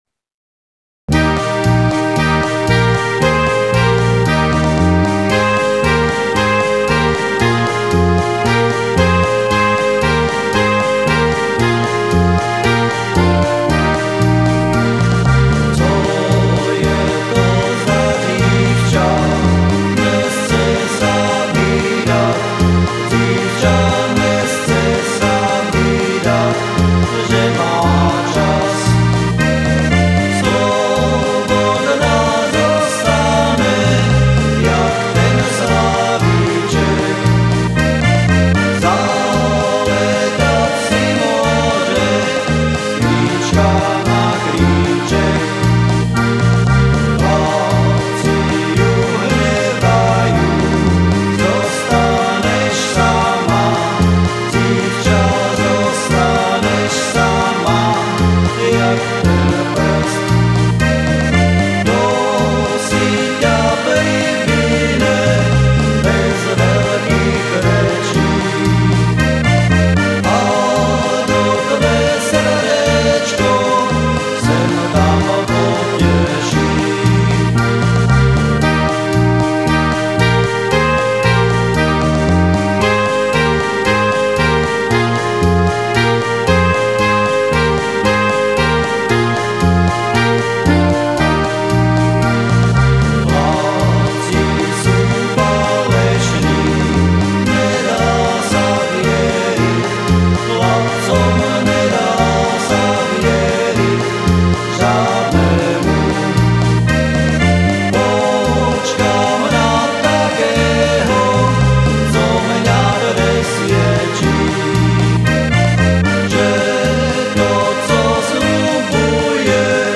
Som amatérsky muzikant, skladám piesne väčšinou v "záhoráčtine" a tu ich budem prezentovať.